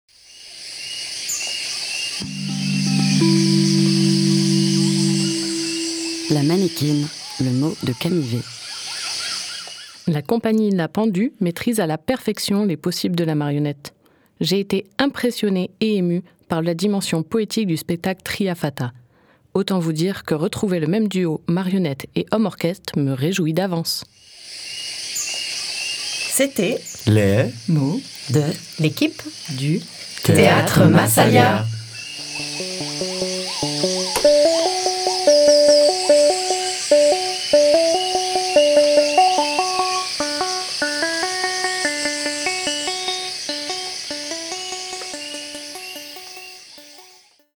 Le mot de l'équipe